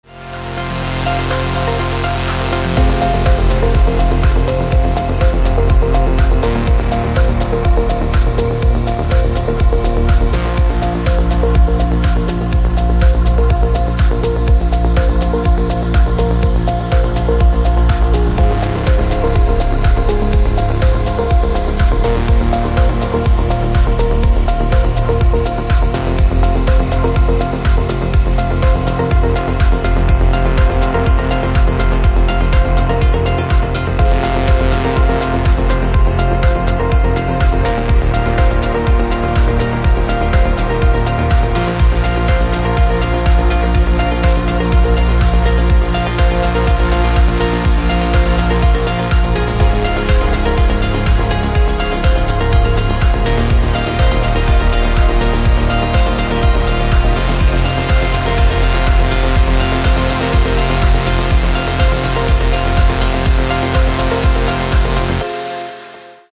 для будильника